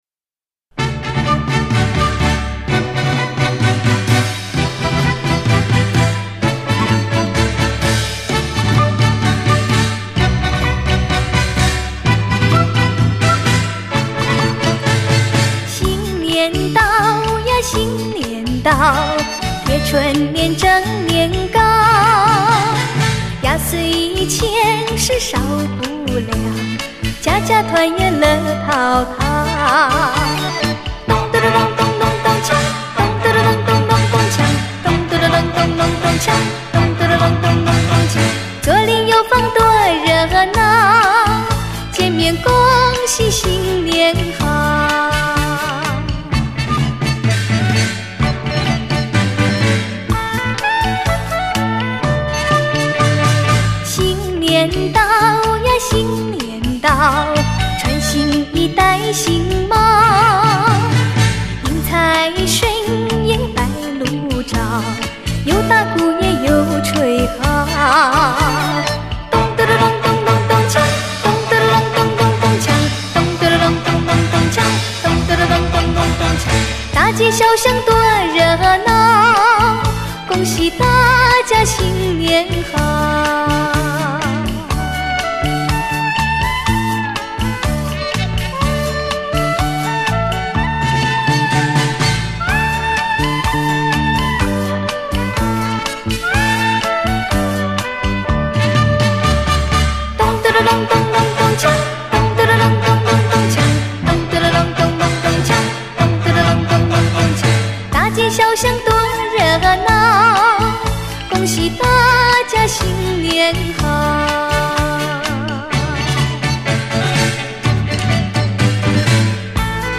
最喜气的年节专辑